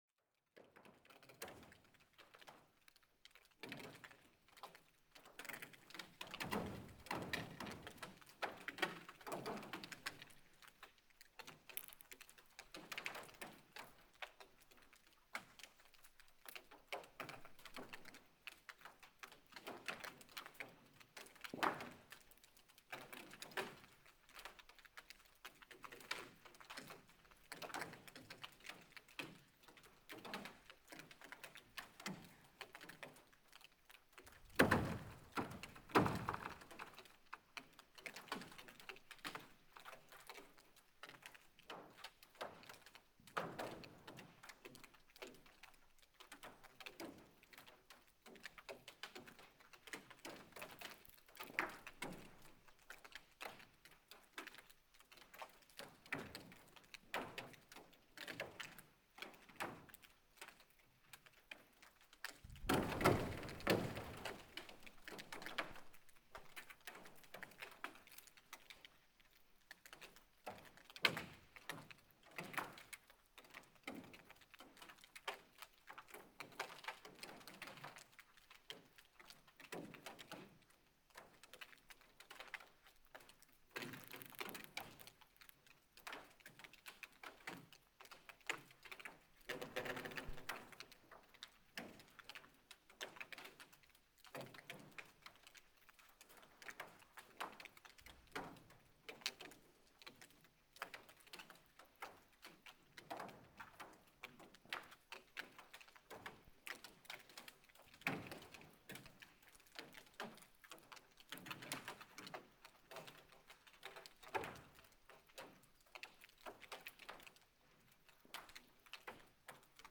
Solar radiation, meteor, burping fish and blizzard
This incoherent and rather strange headline was the result of my last sound recording under the ice on Skorradalsvatn in western Iceland on 14th of March 2026.
I considered it unnecessary to go where the depth was the greatest on the ice but the hydrophones went down about 9 meters without touching the bottom. I had 8 meters between the microphones and arranged them so that I would get a good stereo image from the deepest part of the lake.
However, I could not hear anything in the ice on the surface, which I thought could be because the snow on the ice dampened all surface sounds.